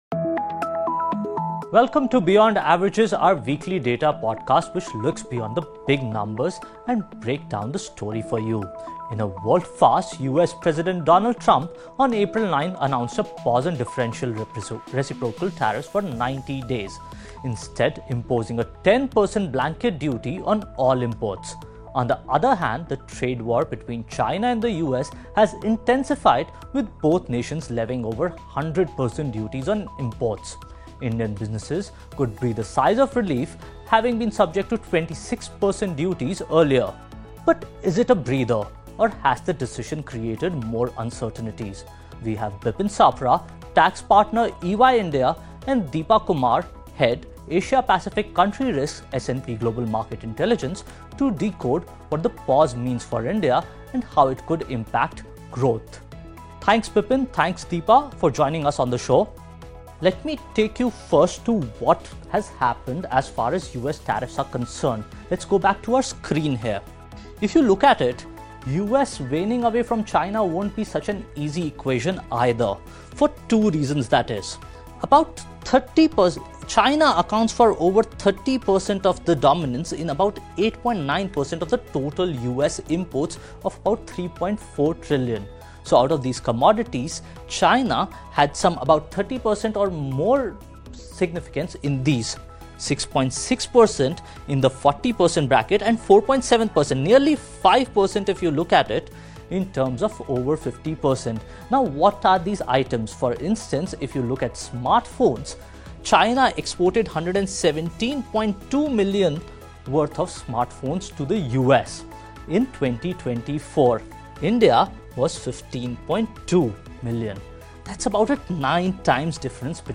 So, where does India stand in this? Listen to the conversation